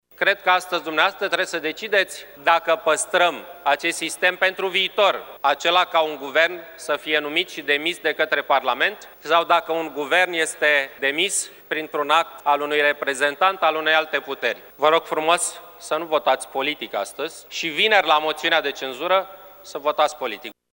In alocuțiunea rostită în fața colegilor săi, Victor Ponta le-a cerut deputaţilor să nu voteze politic în cazul său, ci să exprime un vot politic doar la moţiunea de cenzură: